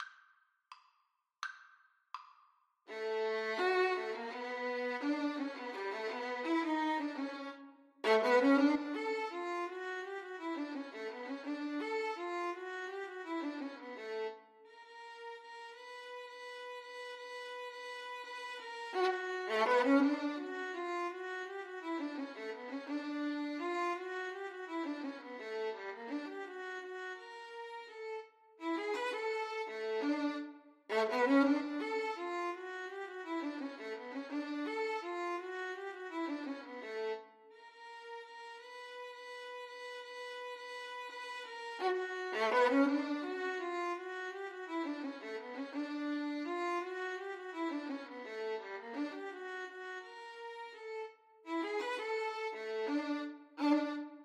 Violin 1Violin 2
= 84 Slow March Time
2/4 (View more 2/4 Music)
Jazz (View more Jazz Violin Duet Music)